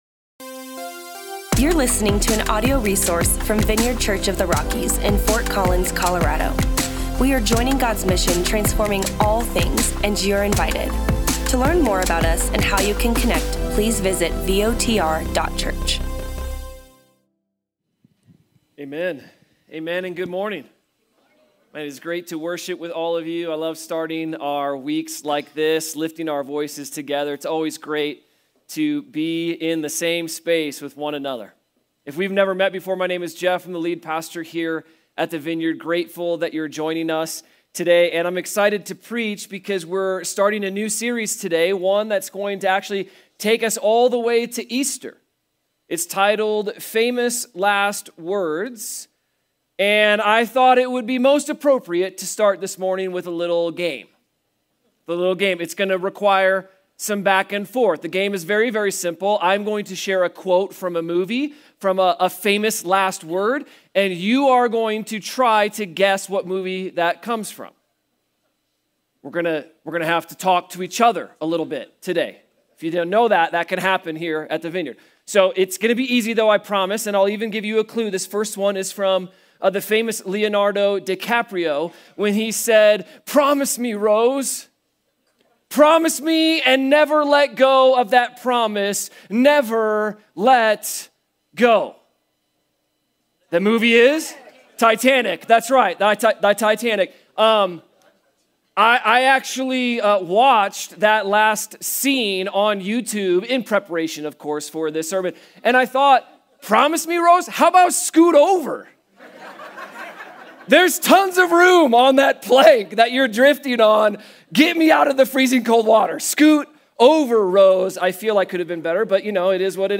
In John 14-17, Jesus gathered His disciples for some final teachings before dying on the cross and inside these four chapters, we learn so much about walking with God. In today’s sermon, we learn that Jesus is making a way for us to be with Him forever and that in the midst of trouble, we can trust Him… What does that mean for us today and how can we draw near to His love?